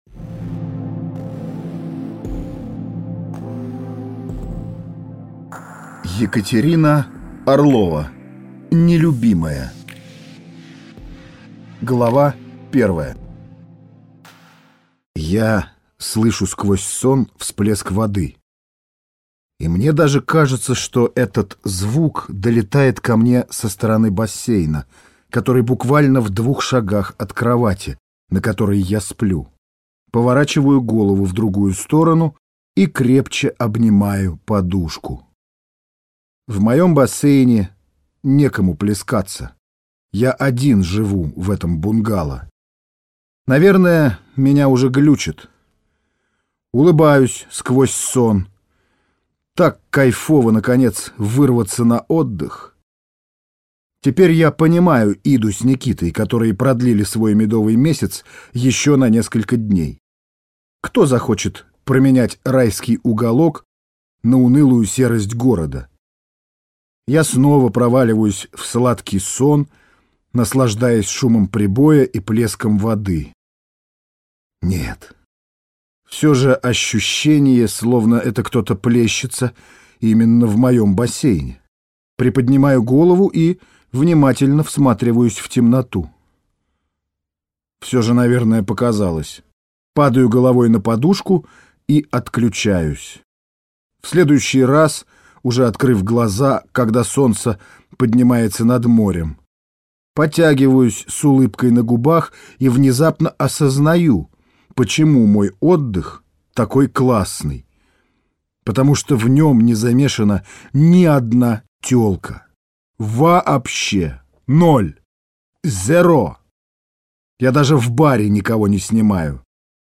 Аудиокнига Нелюбимая | Библиотека аудиокниг